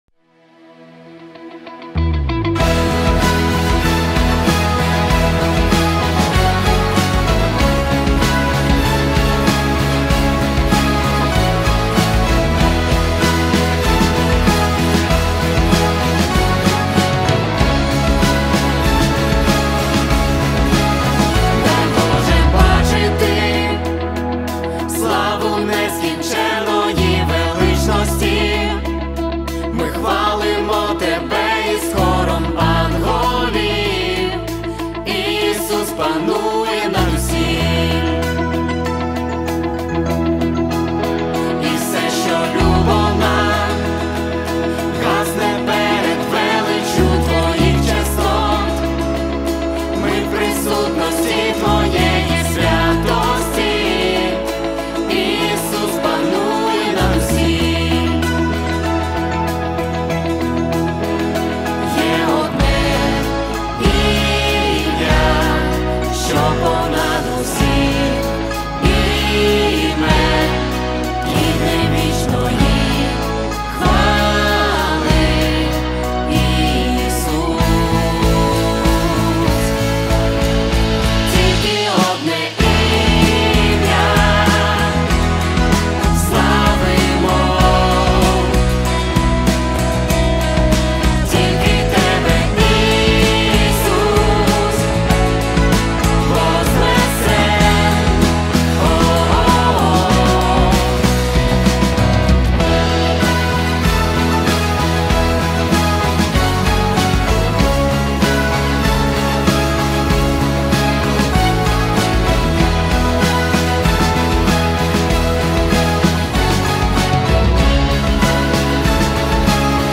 песня
126 просмотров 19 прослушиваний 1 скачиваний BPM: 96